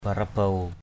/ba˨˩-ra-bau˨˩/ (d.) hằng ngàn, hàng ngàn. thousands. putiray deng di ray barabuw thun p~t{rY d$ d} rY br%b~| E~N hoàng đế trị vị hằng ngàn năm.